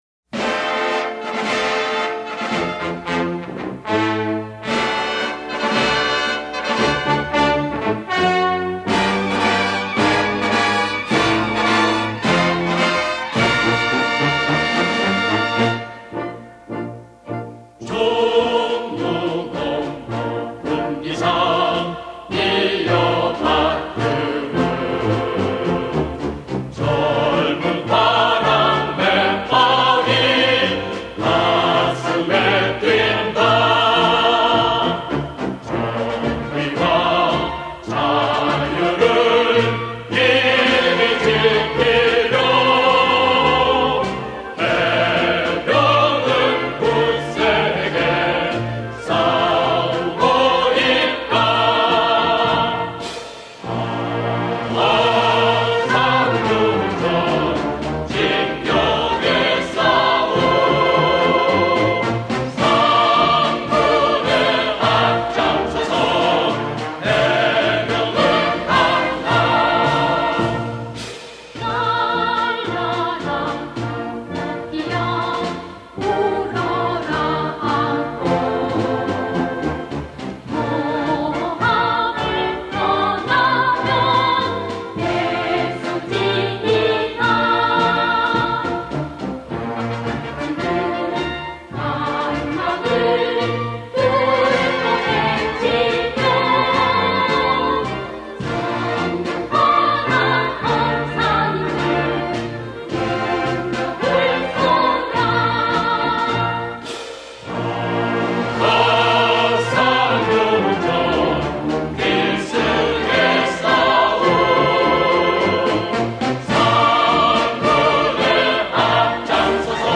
해병대군가음악
▲ 클릭하시면 군가가 재생됩니다.